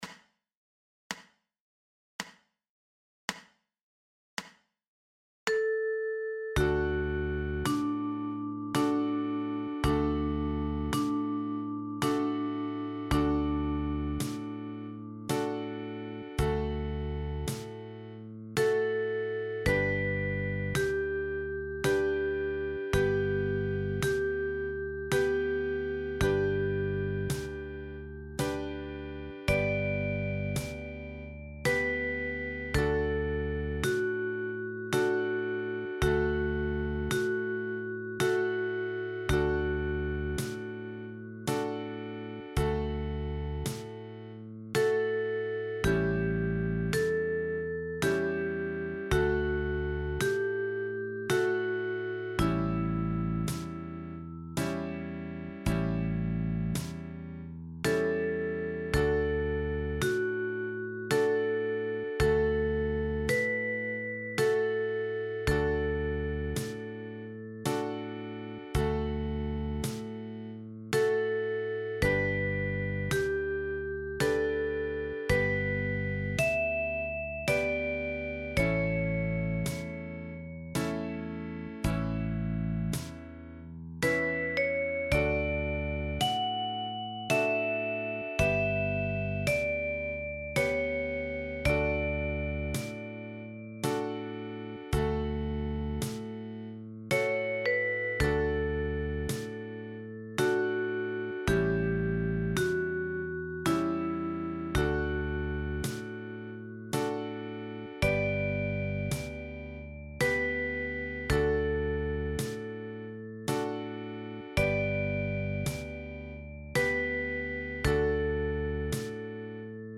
Tin Whistle / Penny Whistle / Pocket Whistle / Low Whistle